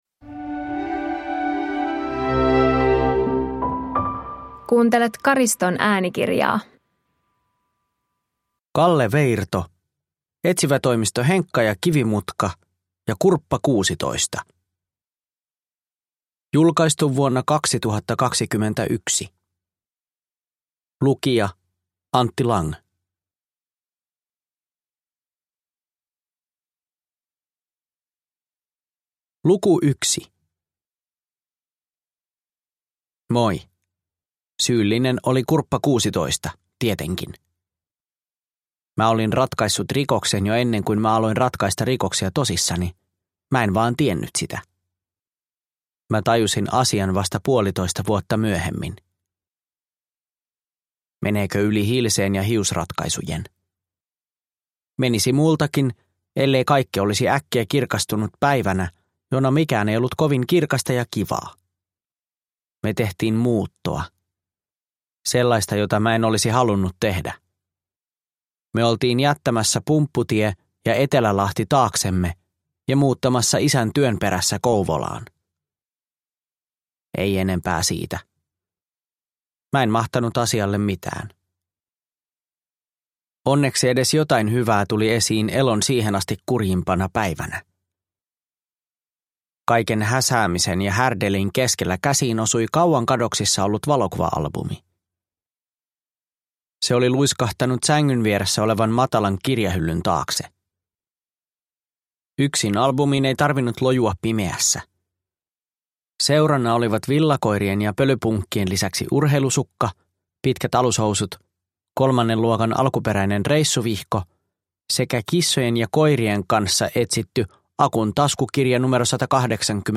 Etsivätoimisto Henkka & Kivimutka ja Kurppa 16 – Ljudbok – Laddas ner